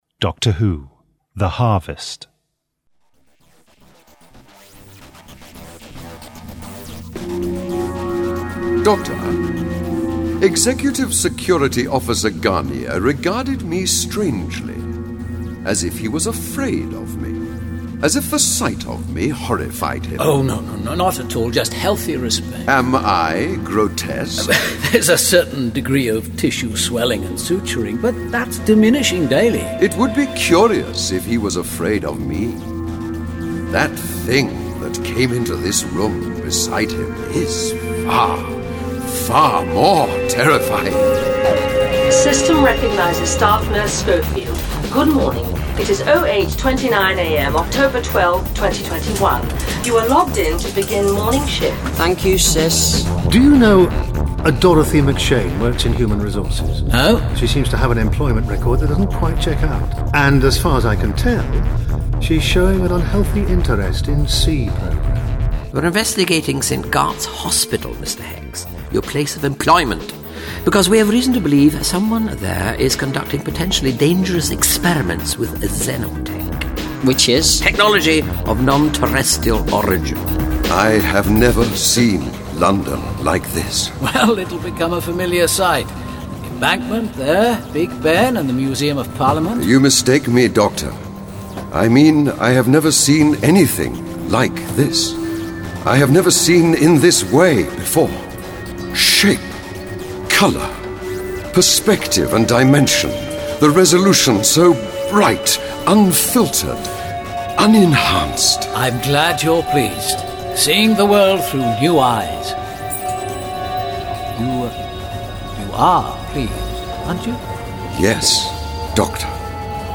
Award-winning, full-cast original audio dramas
Starring Sylvester McCoy Sophie Aldred